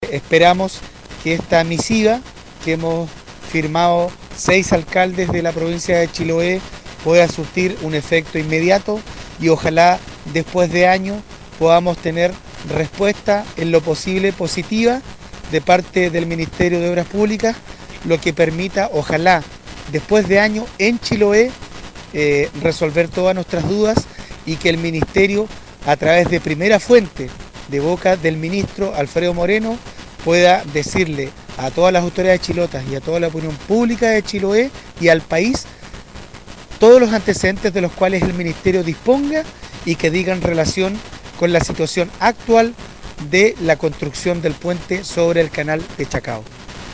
Dijo Marcos Vargas que era imprescindible conocer qué es lo que está ocurriendo con esta esperada obra para el país.